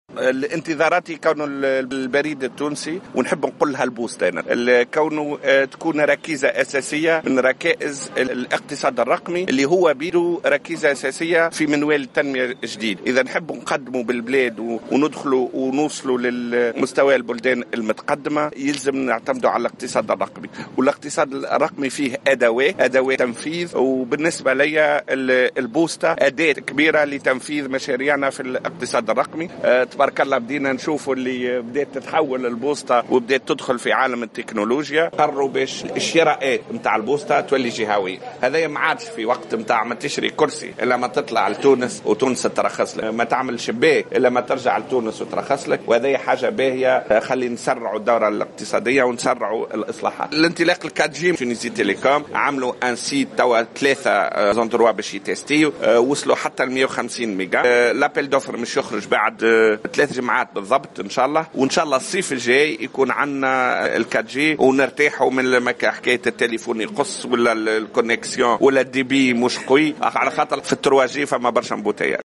أشرف وزير تكنولوجيات الاتصال والاقتصاد الرقمي، نعمان الفهري، اليوم السبت في الحمامات على تظاهرة للاحتفال باليوم العالمي للبريد.